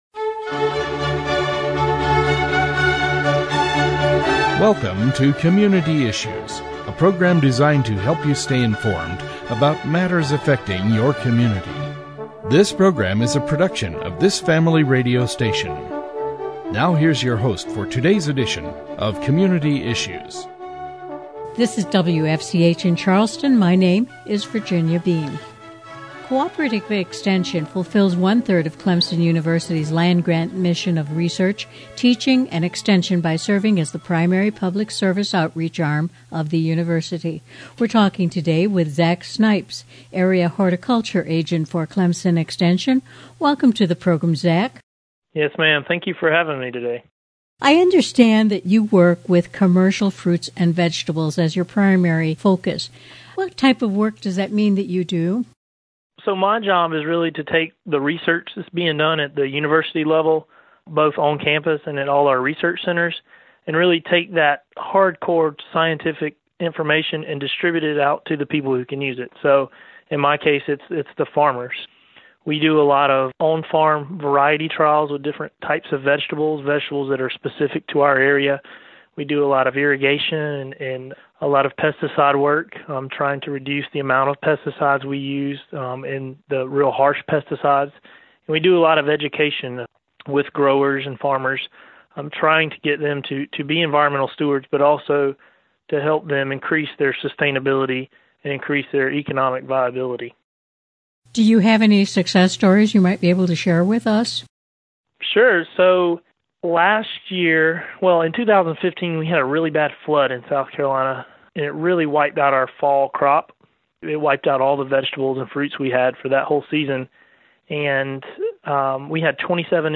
The interview aired on 88.5 FM on Saturday, March 4th.
10379-On_the_Air_Interview.mp3